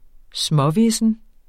Udtale [ ˈsmʌˌvesən ]